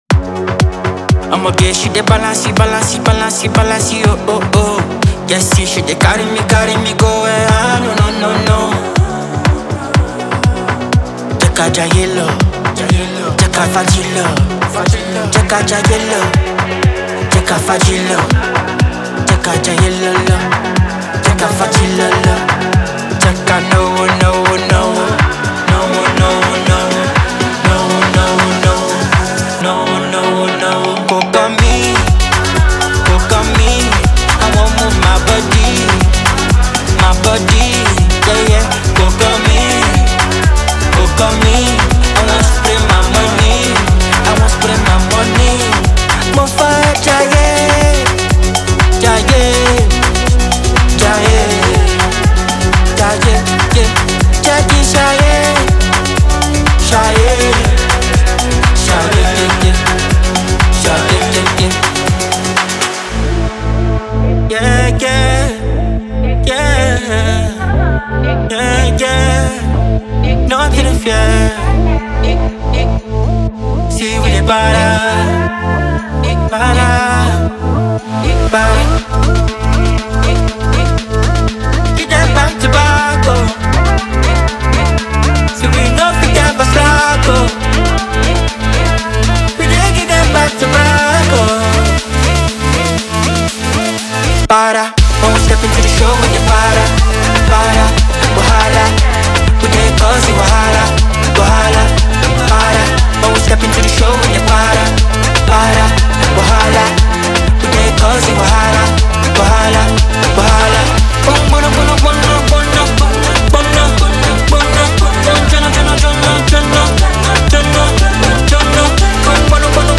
Genre:Afro House
デモサウンドはコチラ↓